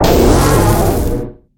Cri de Salarsen dans sa forme Grave dans Pokémon HOME.
Cri_0849_Grave_HOME.ogg